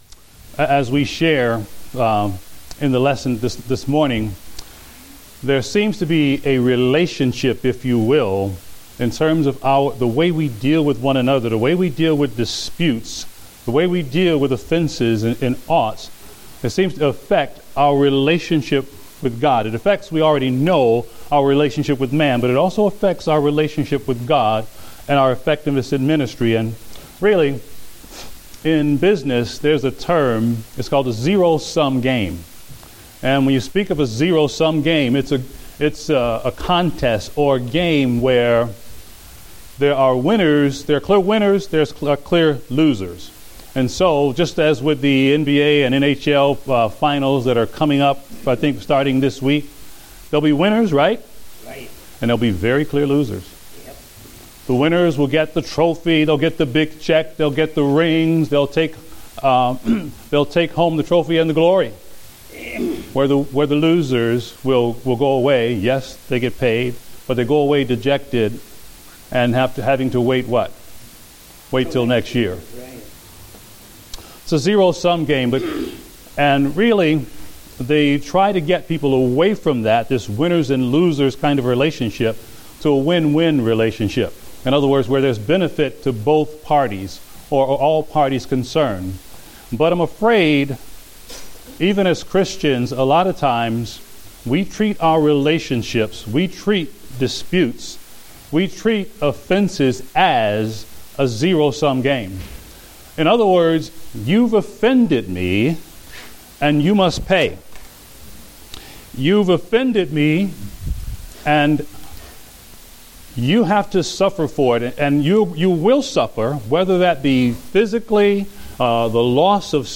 Date: May 31, 2015 (Adult Sunday School)